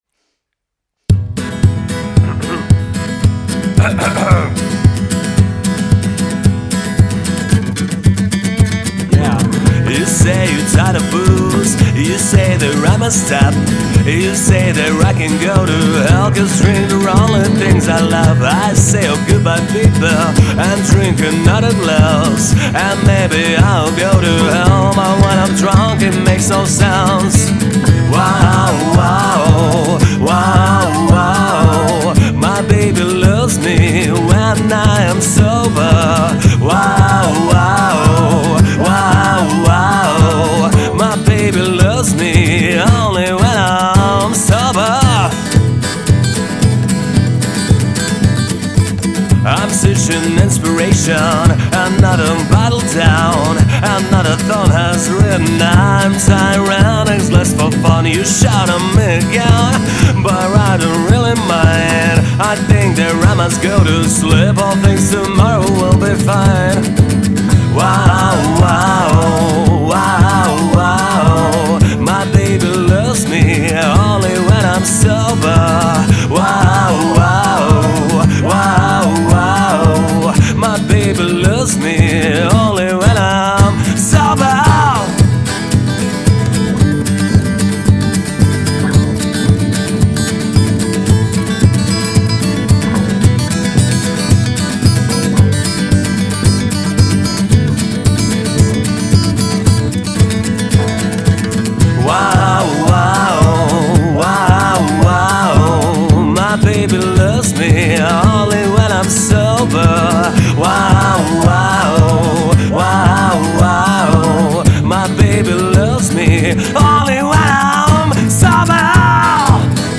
Mid-side запись гитары на микрофон и в линию
Но зато никаких обработок, сыряк-сыряком, сделанный за час со всеми сочинениями, записями и "сведениями" В самом начале там почти чистая гитара играет (мешает ей только псевдо-бочка из гитары сделанная, а потом еще и вступает псевдо-шейкер, тоже из гитары), вот она записана по принципу 12/5 и спанорамирована 100L/100R.